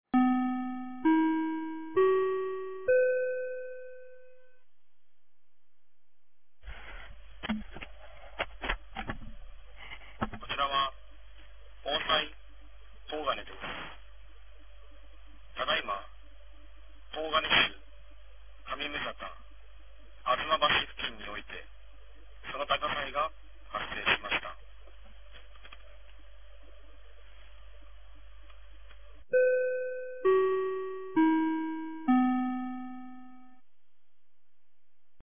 2025年01月10日 11時51分に、東金市より防災行政無線の放送を行いました。